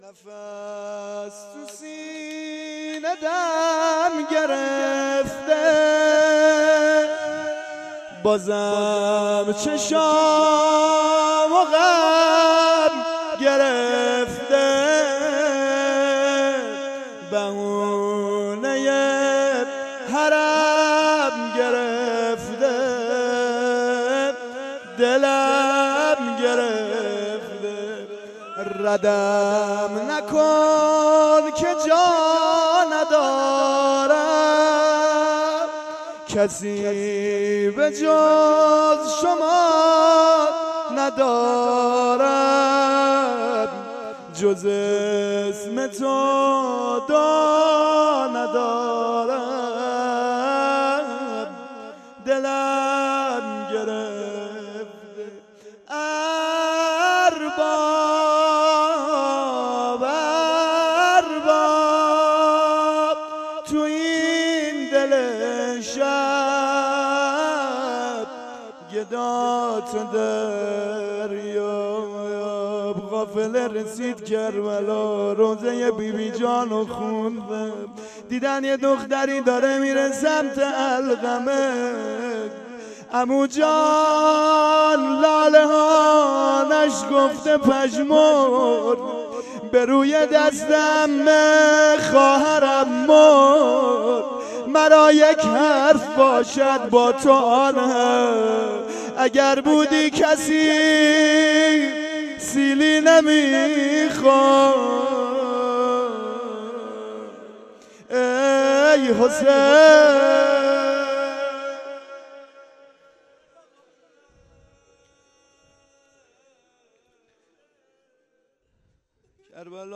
05-arbaeen93-roze.mp3